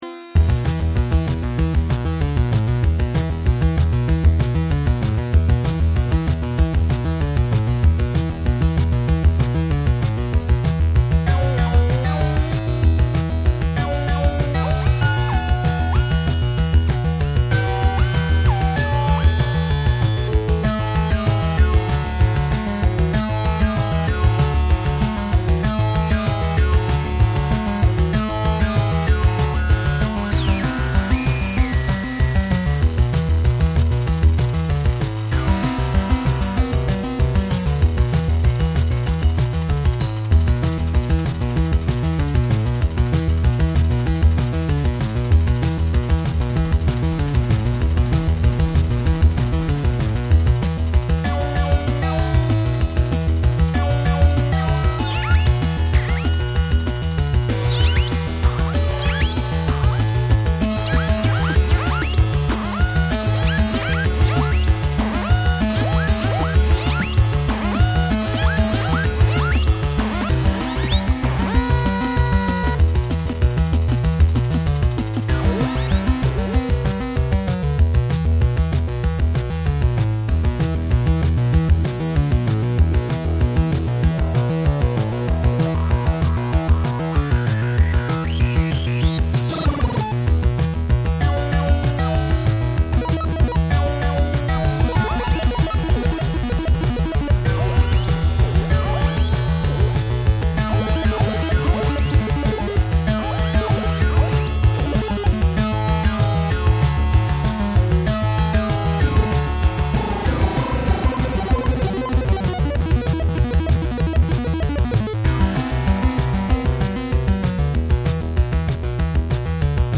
In alcuni episodi di chitarra
batteria
Odori e profumi si confondono in un’unica massa sonora